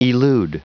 Prononciation du mot elude en anglais (fichier audio)
Prononciation du mot : elude